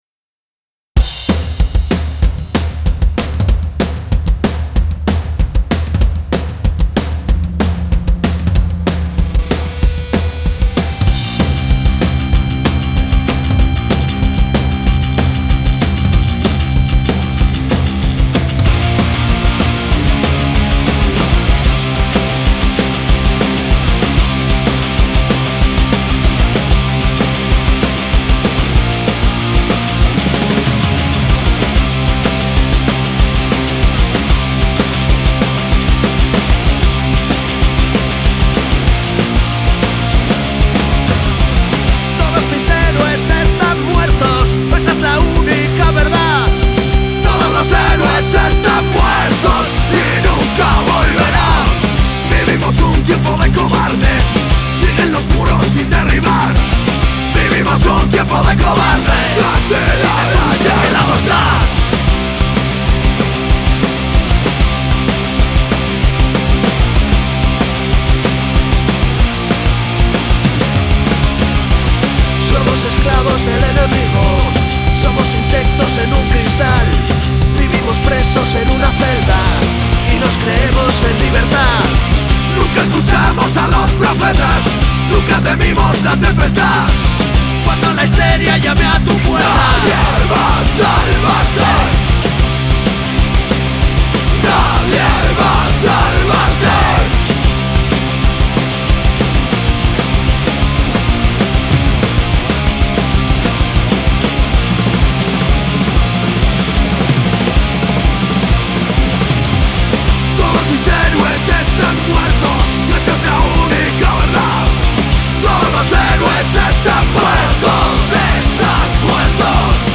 rock.mp3